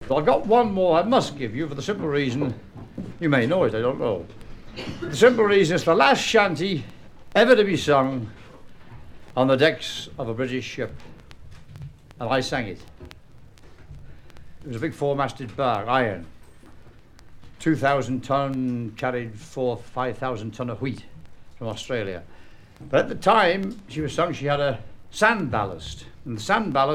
présentation de chansons maritimes
Catégorie Témoignage